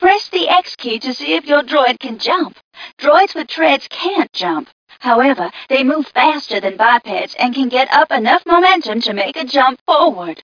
1 channel
mission_voice_tgca042.mp3